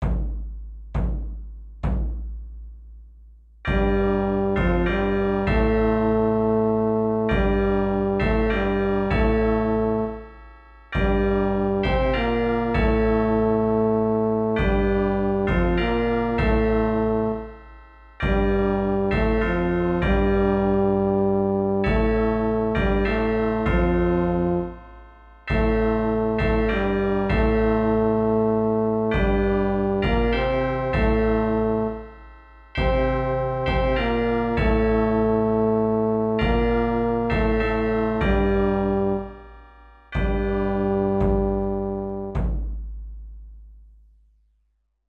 (tenor) |
004-tenor.mp3